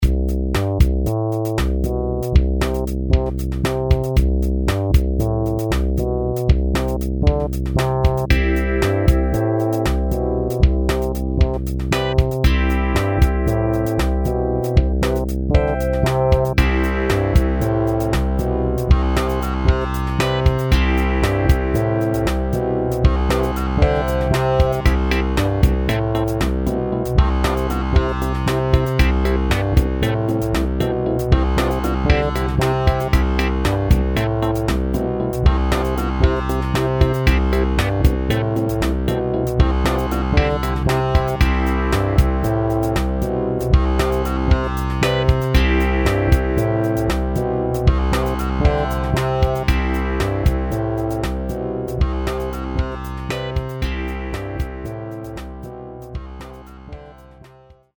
DX7 EPiano - 130mbDecember, 2007
The famous DX7 Electric Piano Sound is captured faithfully in this large soundset.
All other sounds are also from the upcoming "FM-Classic Synth" sample pack, and all of the sounds are played using ManyBass.
Manytone_DXTX_Demo2edit.mp3